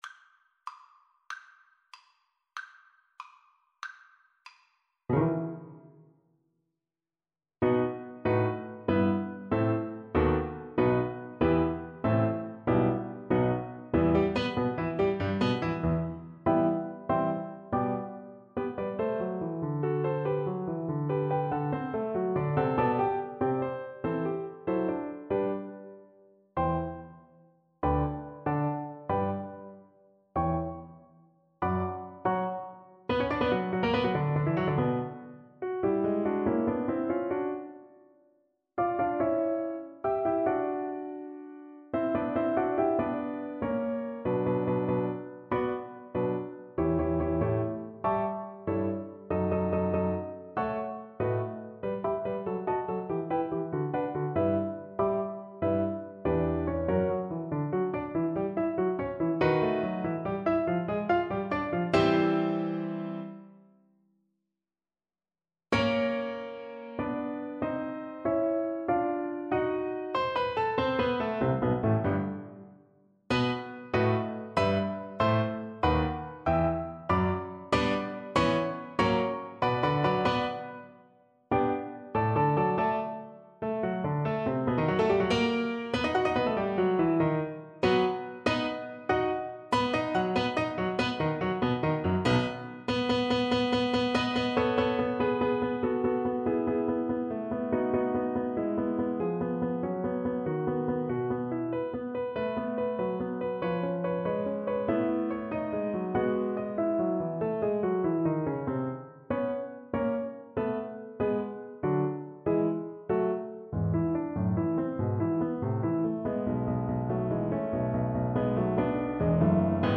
6/8 (View more 6/8 Music)
Allegro scherzando .=95 (View more music marked Allegro)
Classical (View more Classical Bassoon Music)